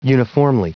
Prononciation du mot uniformly en anglais (fichier audio)
Prononciation du mot : uniformly